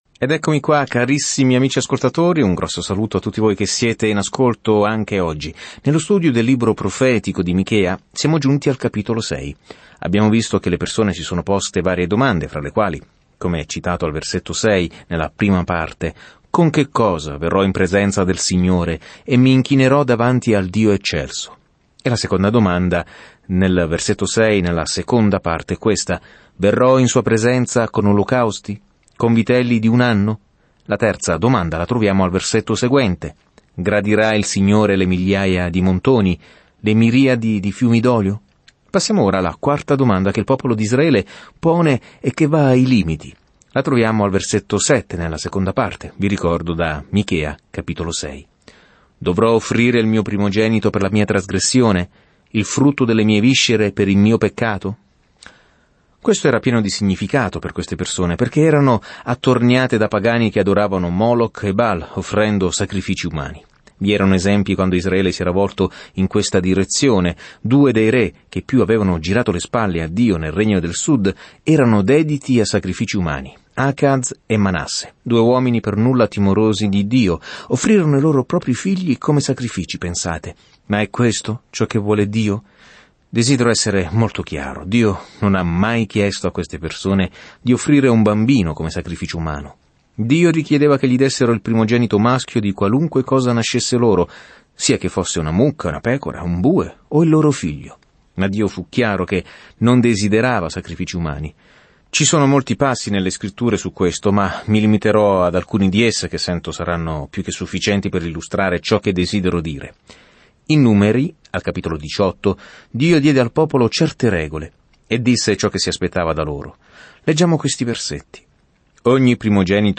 Scrittura Michea 6:8-15 Giorno 7 Inizia questo Piano Giorno 9 Riguardo questo Piano In una bella prosa, Michea invita i leader di Israele e Giuda ad amare la misericordia, ad agire giustamente e a camminare umilmente con Dio. Viaggia ogni giorno attraverso Michea mentre ascolti lo studio audio e leggi versetti selezionati della parola di Dio.